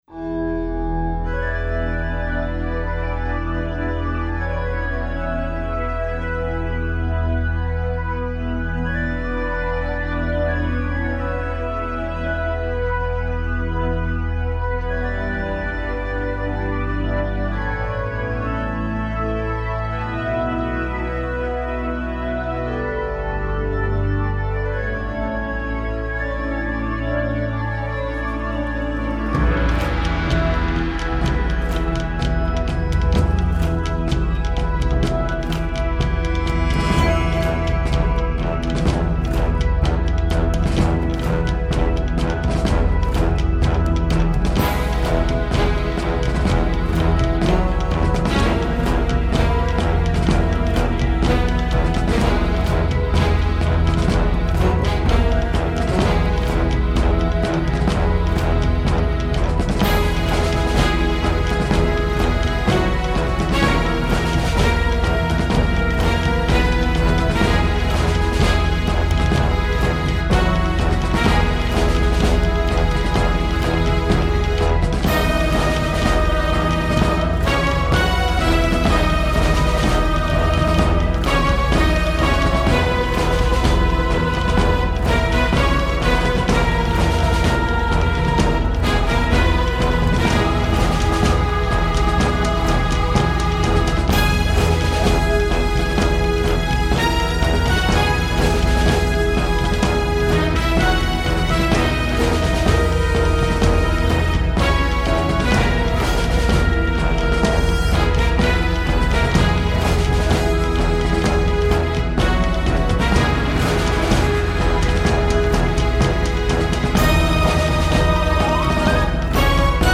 Точнее, знаки при ключе :) Ми мажор или ми минор?